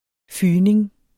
Udtale [ ˈfyːneŋ ]